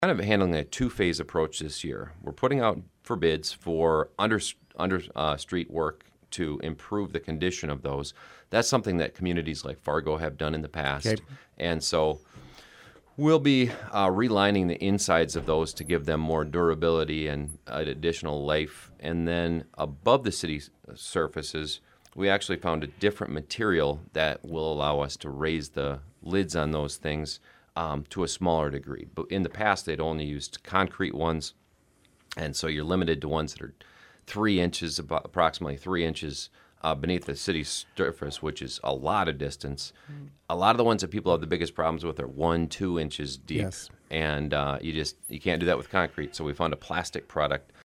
On Wednesday he appeared on the KSDN Midday Report to talk more about that.